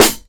Snare
Original creative-commons licensed sounds for DJ's and music producers, recorded with high quality studio microphones.
Dry Snare Sound A# Key 93.wav
dry-snare-sound-a-sharp-key-93-Wb3.wav